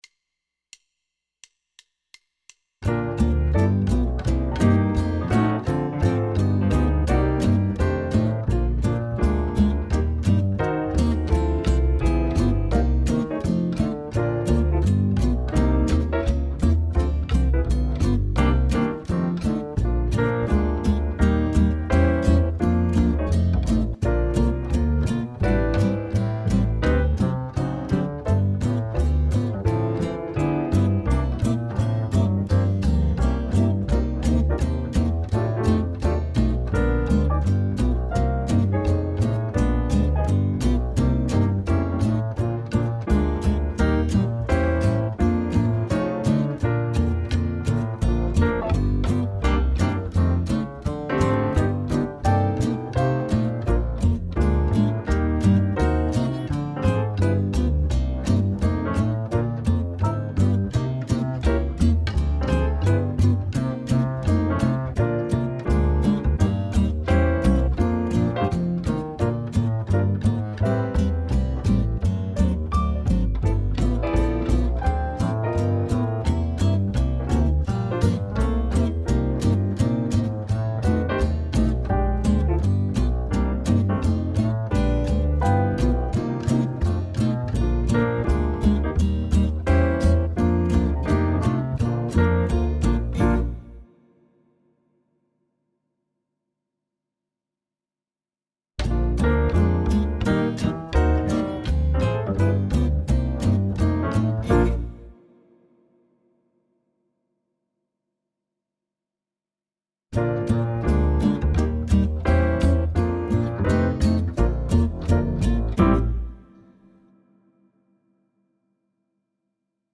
2025-I-Got-Rhythm-Drums.mp3